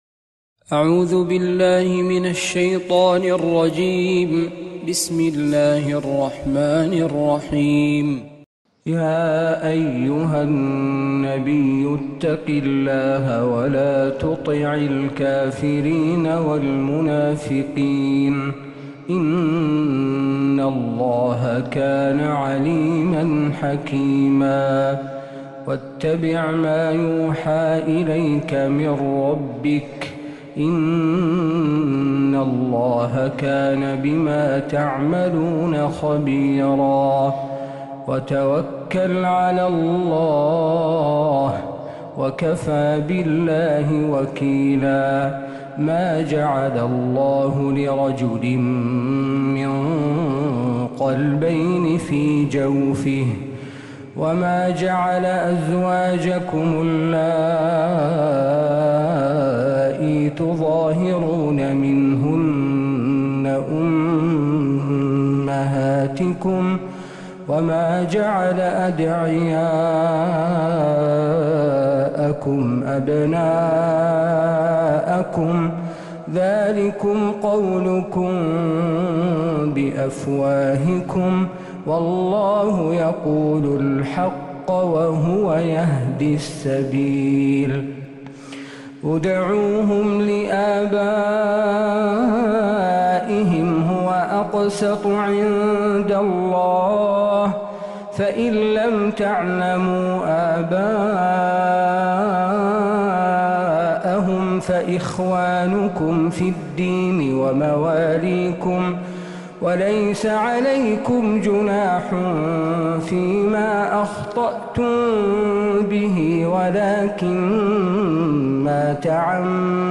سورة الأحزاب كاملة من فجريات المسجد النبوي للشيخ د. عبدالله القرافي | جمادى الآخرة 1446هـ > السور المكتملة للشيخ عبدالله القرافي من الحرم النبوي 🕌 > السور المكتملة 🕌 > المزيد - تلاوات الحرمين